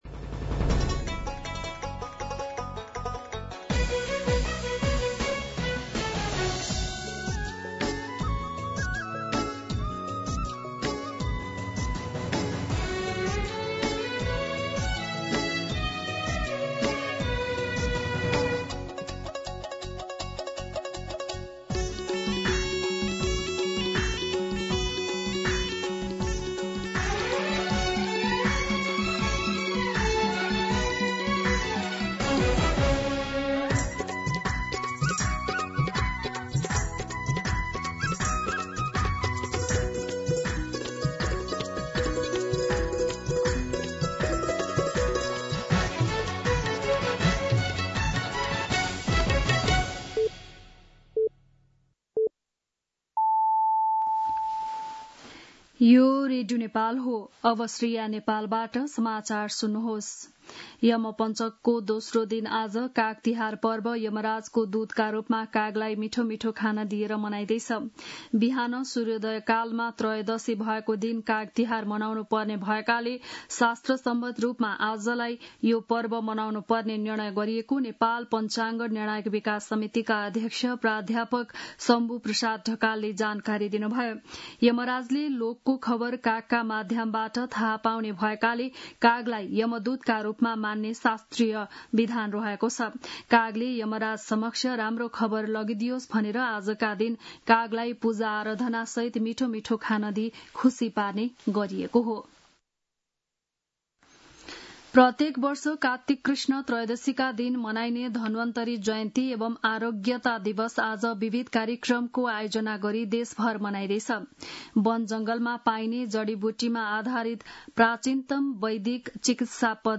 बिहान ११ बजेको नेपाली समाचार : १८ पुष , २०२६
11-am-Nepali-News-7.mp3